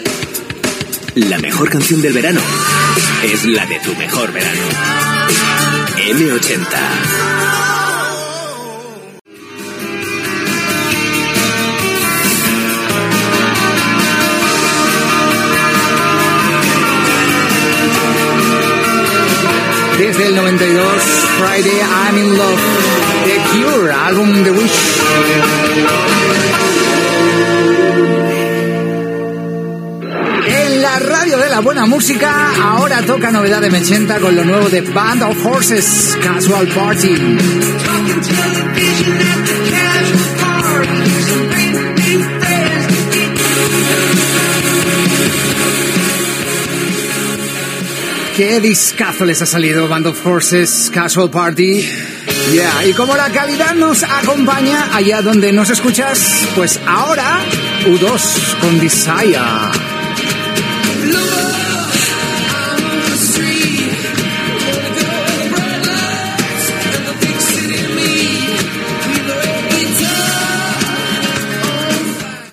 Tema musical, indicatiu, comiat del locutor.